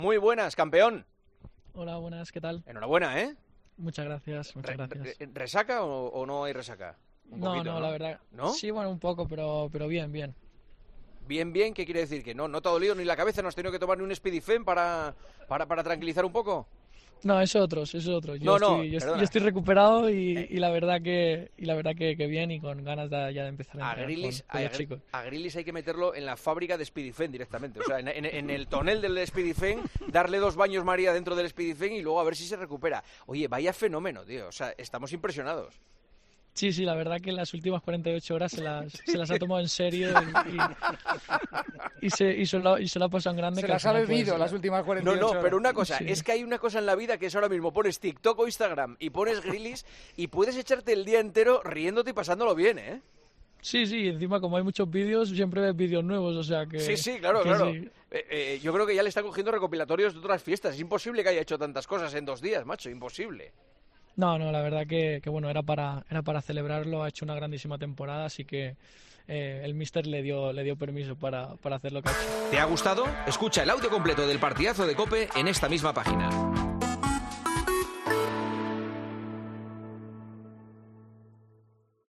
Sergio Gómez, jugador del City, habló con el Partidazo de COPE tras el partido amistoso de la Selección sub-21 y repasó la fiesta del equipo inglés.